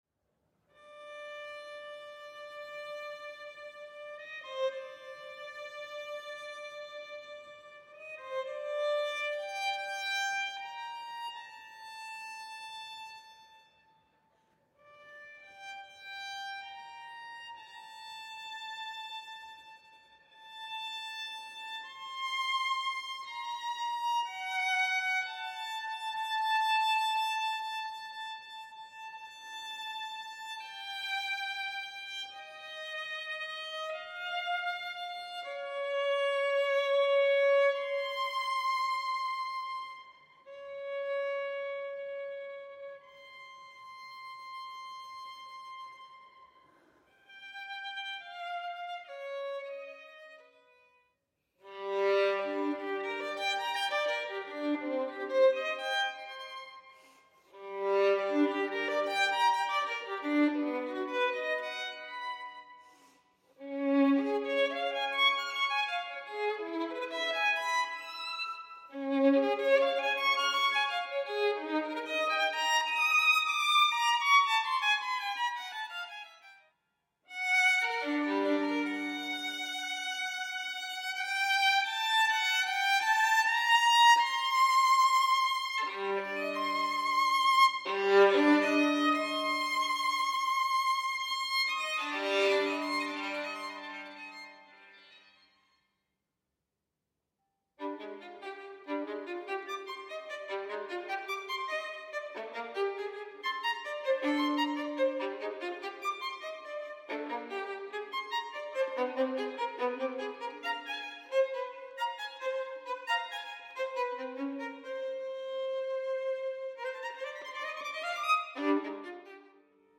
Contemporary Music from Iceland for Solo Violin